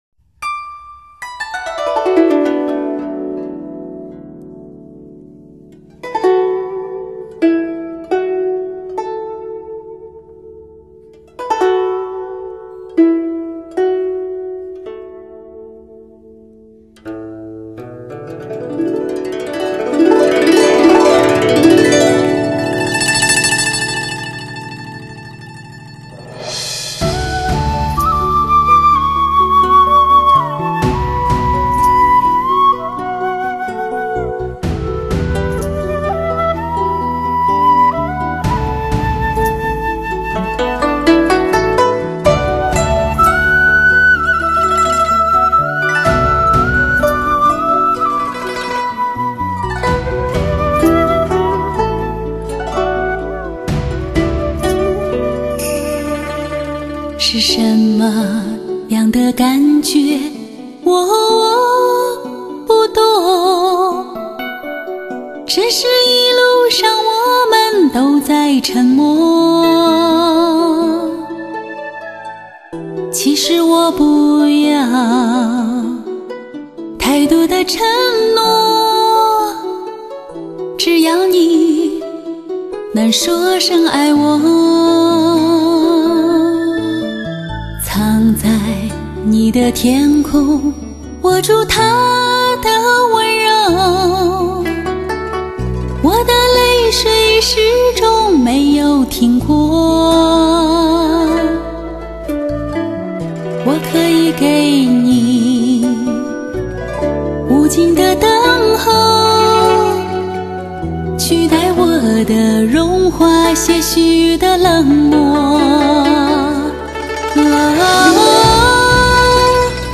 中国首张由著名女歌唱家演唱的HIFI发烧唱片，
中国新民歌天后，钻石级新民歌桂冠女皇，她的声音最值得在高保真的音响中鉴赏， 她是当今中国最有味道的极致女声。